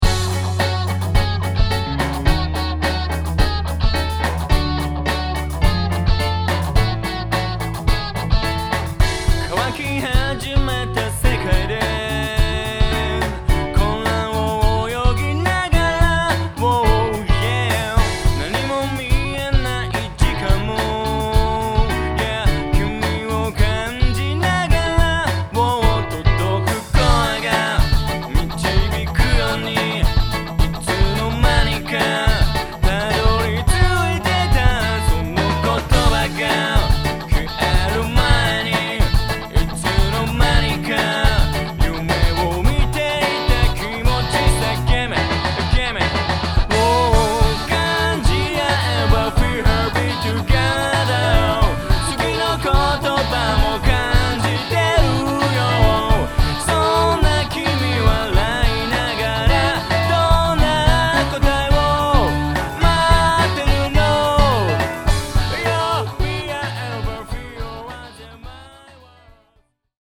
そのブルーアイドソウルの日本版、アーモンドアイドソウルあるいはイエロースキンドソウル、 とでもいいましょうか、そんな匂いを感じます。